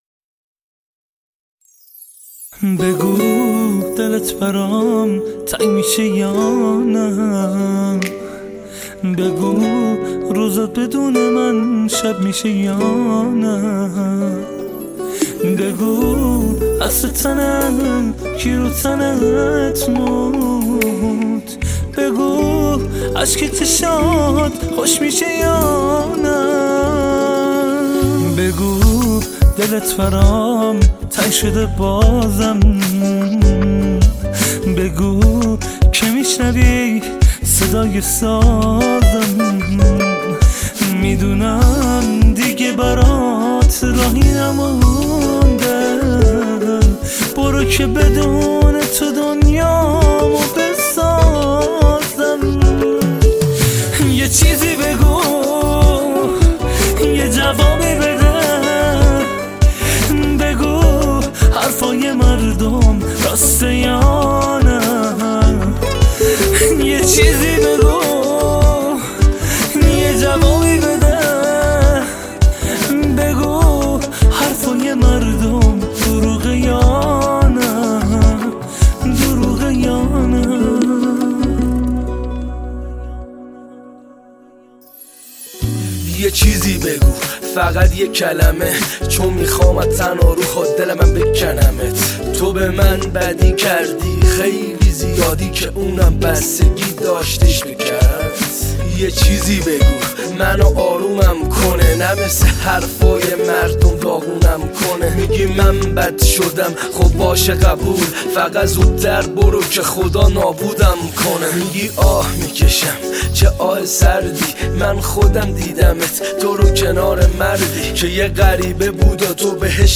تک اهنگ ایرانی